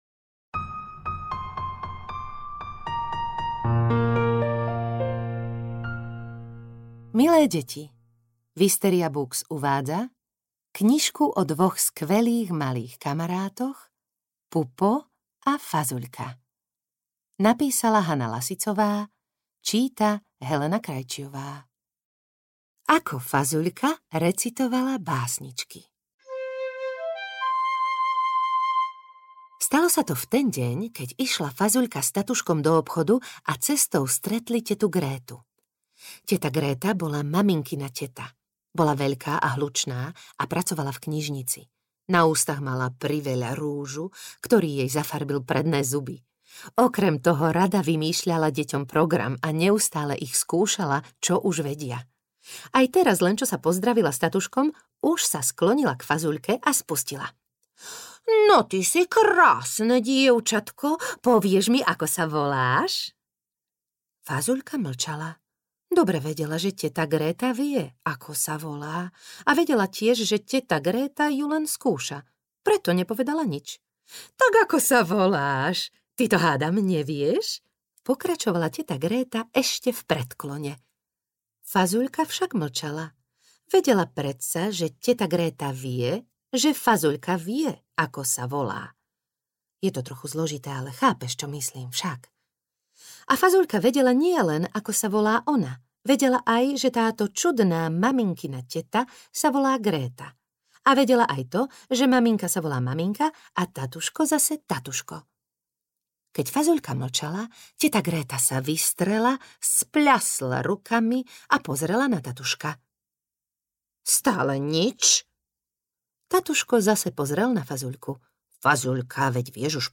Pupo a Fazuľka audiokniha
Ukázka z knihy
• InterpretHelena Krajčiová